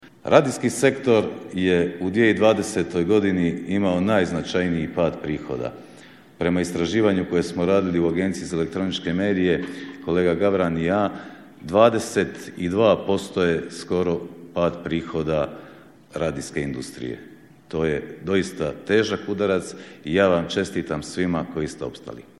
Dani HURiN-a održavaju se u Svetom Martinu na Muri u organizaciji Hrvatske udruge radijskih nakladnika, a u partnerstvu Zaklade Konrad Adenauer, HAKOM-a (Hrvatske regulatorne agencije za mrežne djelatnosti) i OIV (Odašiljača i veza).
Sve to pokazuje da se unatoč teškim vremenima teži očuvanju struke i jačanju kvalitete programa, poručio je predsjednik Agencije za eletroničke medije (AEM) Josip Popovac te dodao: